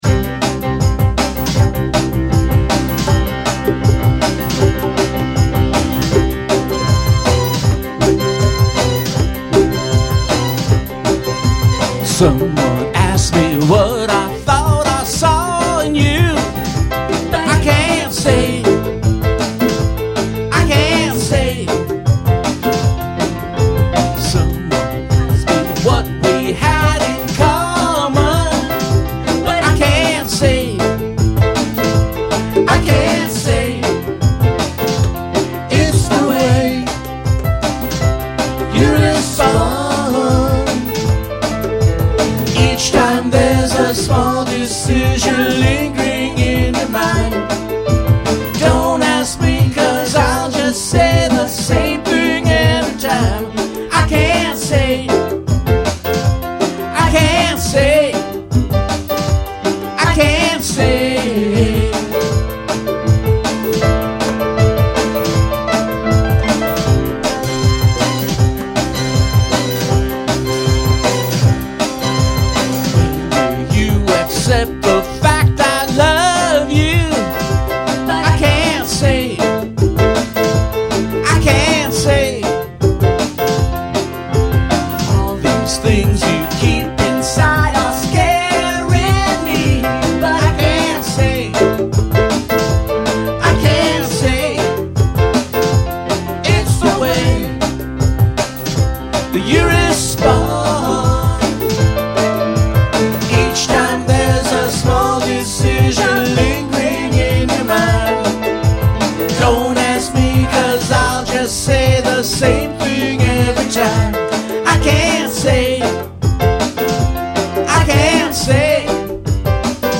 Bass and Guitar
Congas, percussion
Vocals
Keyboards, vocals